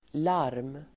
Uttal: [lar:m]